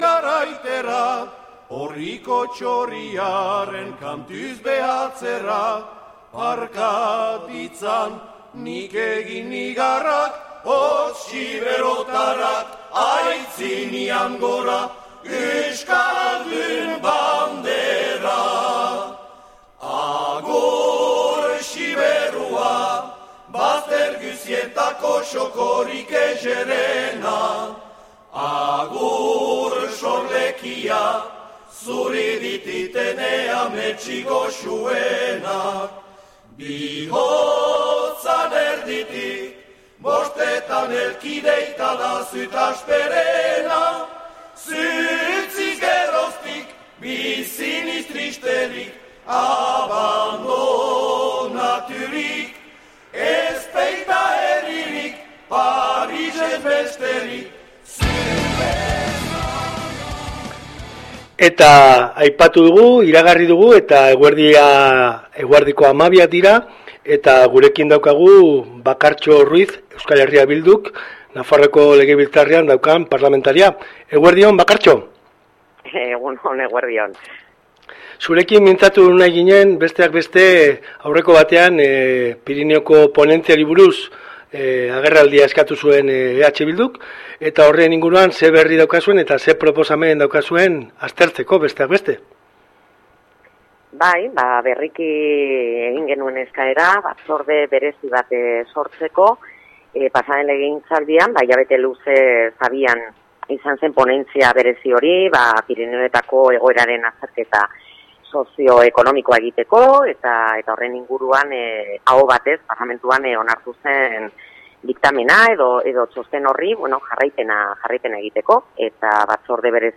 Bakartxo Ruiz EH Bilduko parlamentaria izan dugu gaur Irati irratian. Pirinioko ponentzia berreskuratzea lortu du EH Bilduko Nafarroako parlamentuan eta hemendik aurrerako urratsei buruz aritu da gurean.